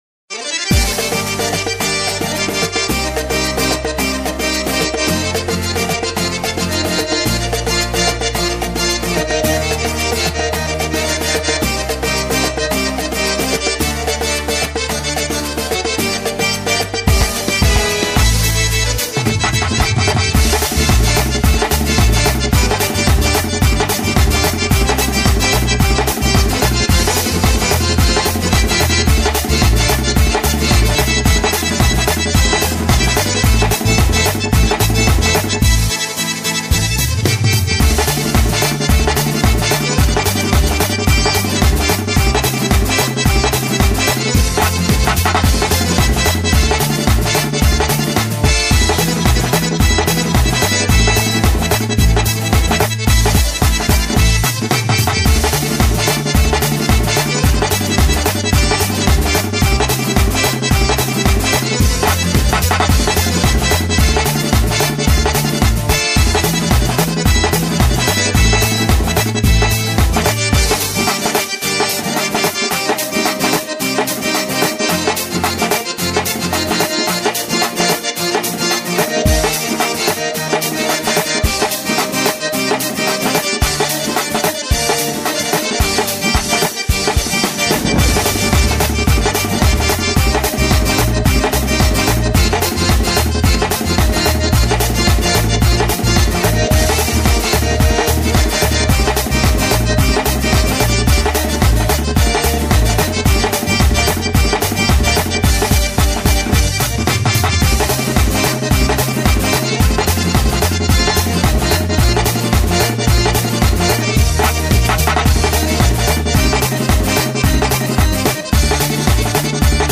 Категория: лезгинки